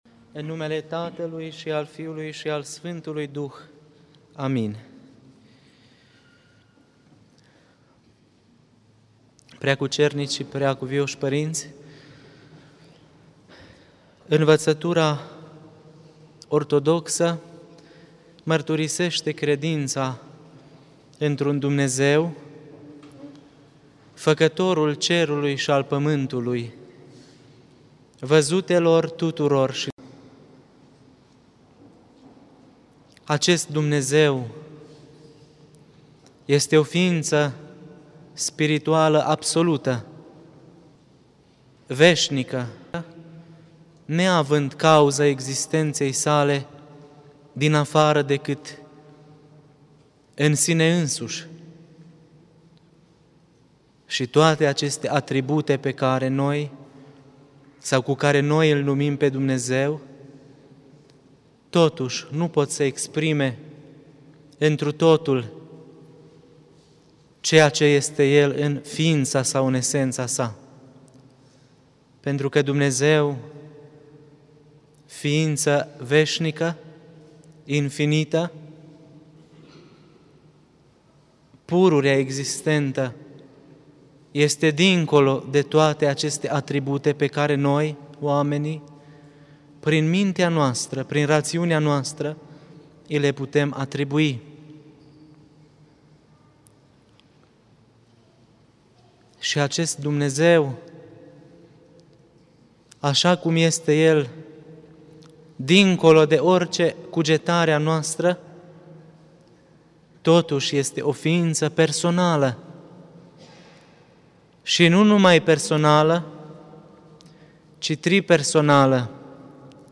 Cuvinte de învățătură Predică la sărbătoarea Soborului Sf.